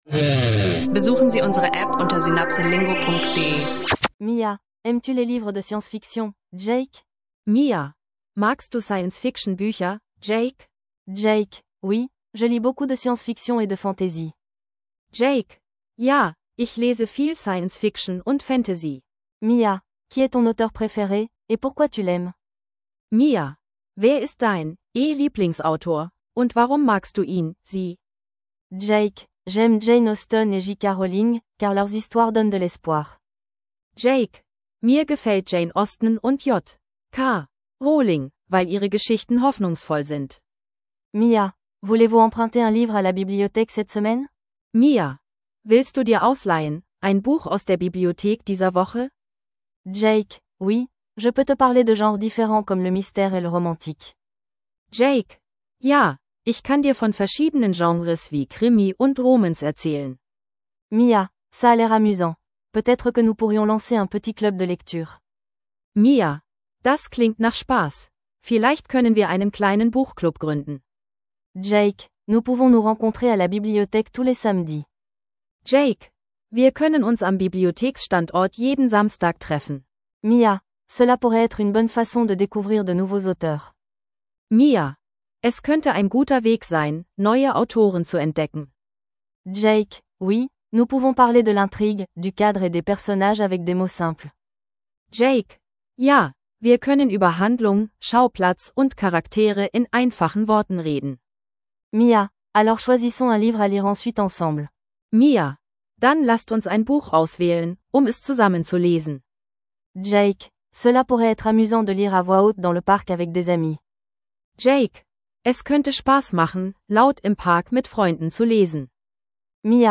kurze Dialoge zu Büchern, Autoren und literarischen Genres.